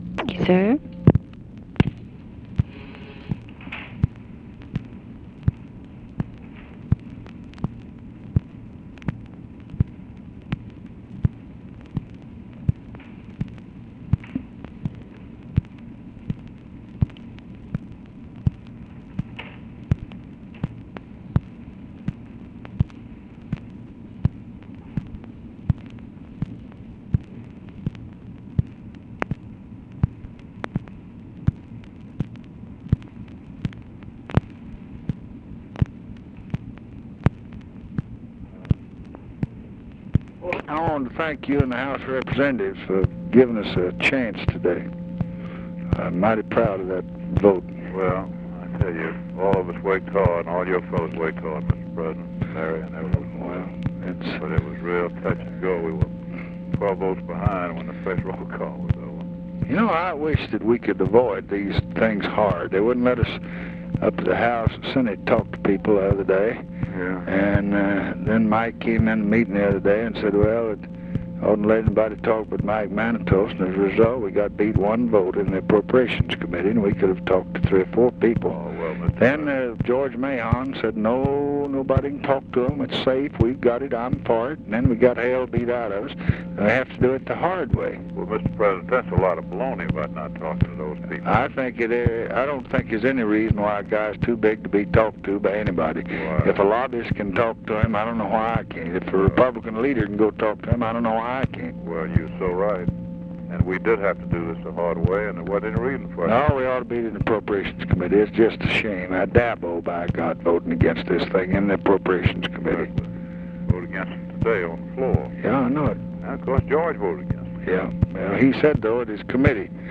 Conversation with HALE BOGGS, May 11, 1966
Secret White House Tapes